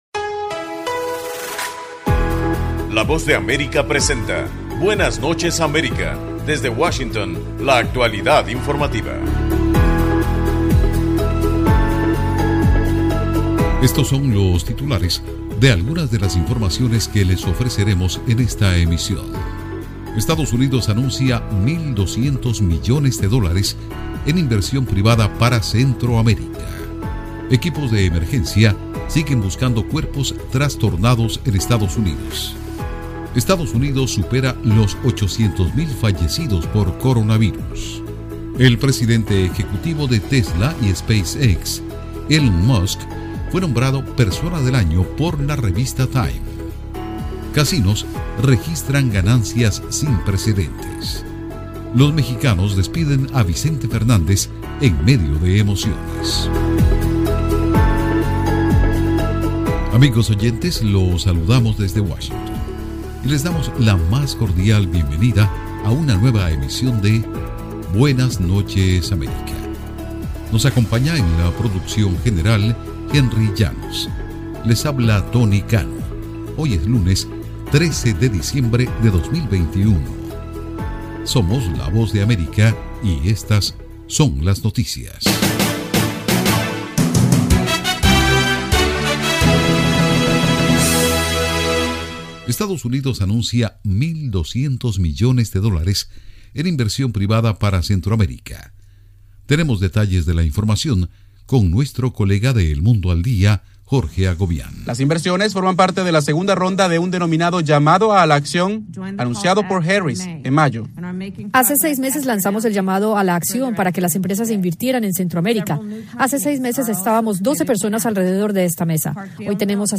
Programa informativo de la Voz de América, Buenas Noches América.